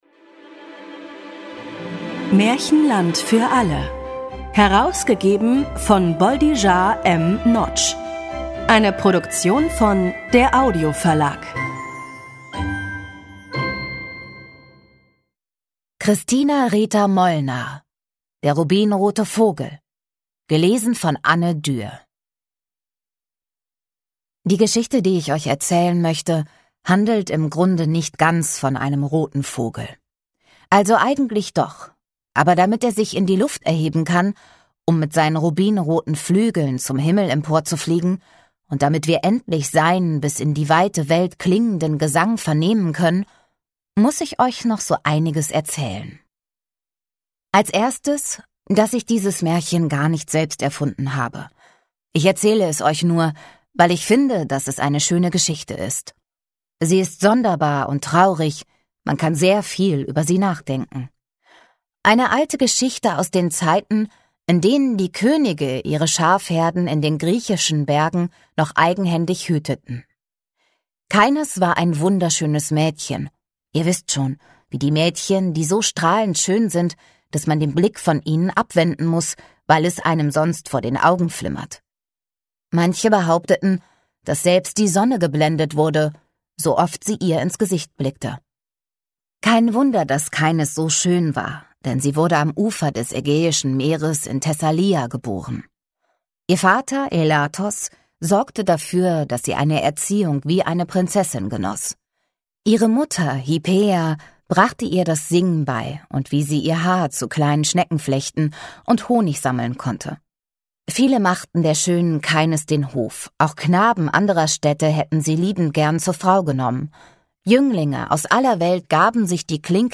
Ungekürzte Lesung mit Musik mit Annette Frier, Christoph Maria Herbst u.v.a. (1 mp3-CD)